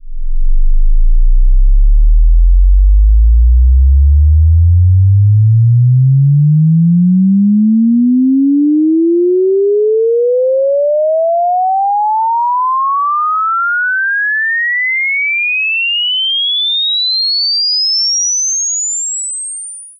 audiocheck.net_headphonesweep20Hz-10kHz.ogg